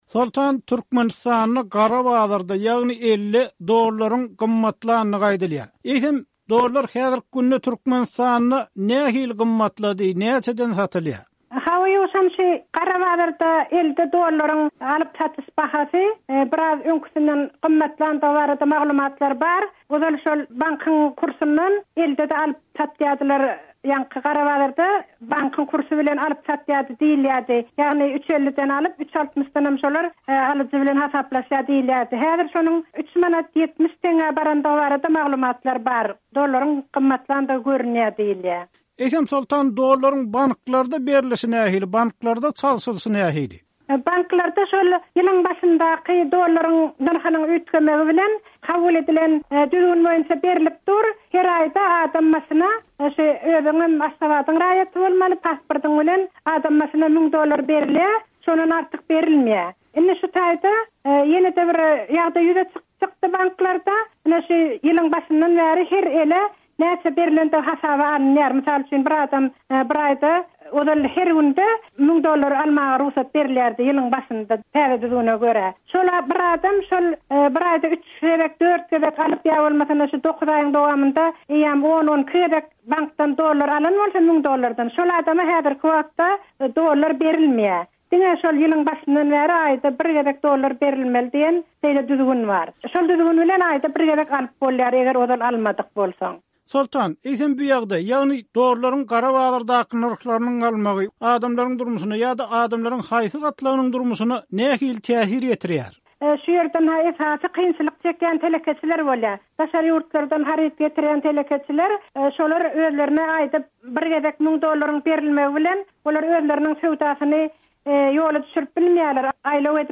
Azatlyk radiosy bu ýagdaý barada aşgabatly žurnalist